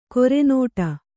♪ kore nōṭa